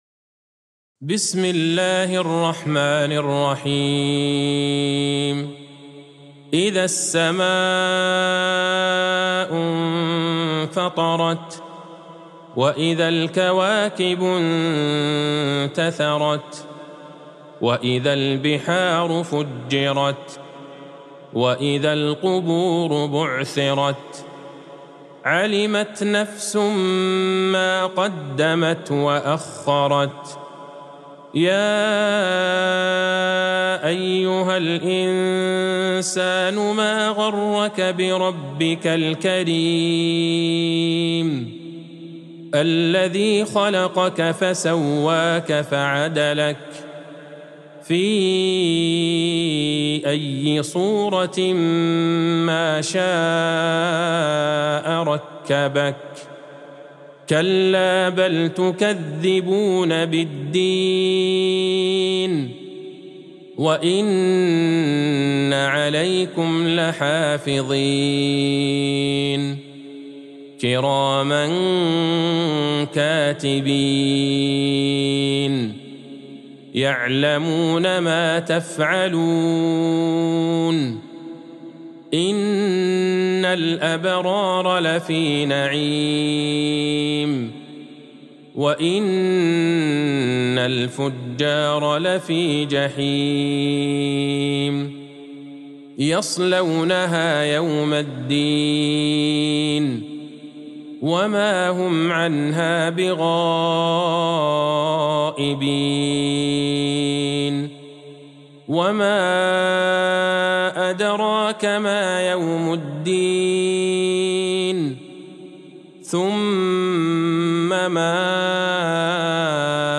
سورة الإنفطار Surat Al-Infitar | مصحف المقارئ القرآنية > الختمة المرتلة ( مصحف المقارئ القرآنية) للشيخ عبدالله البعيجان > المصحف - تلاوات الحرمين